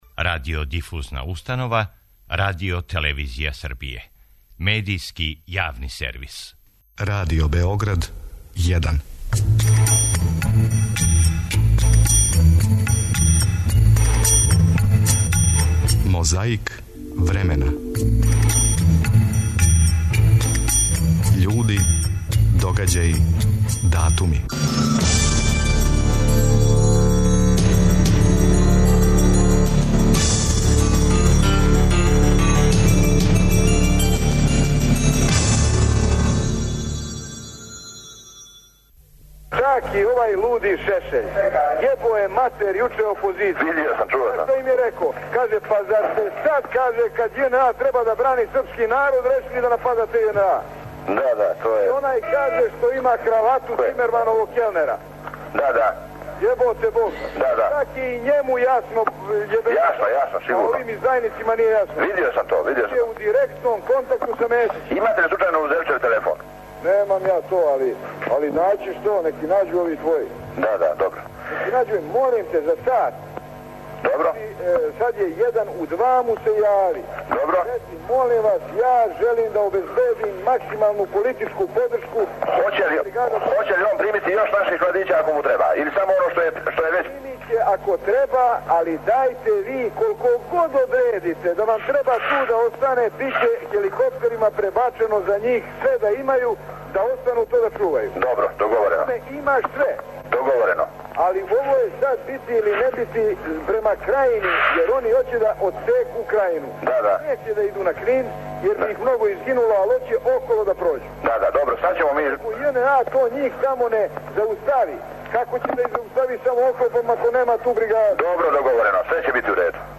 Борбу против пилећег памћења ове суботе отвара „пресретнути“ телефонски разговор између Слободана Милошевића и Радована Караџића, коришћен на суђењу Слободану Милошевићу 23. октобра 2003. године.
А без обзира на промене много чега, па и календара, о Октобарској револуцији у Русији – говоримо у октобру. Помажу нам снимци из Тонског архива Радио Београда.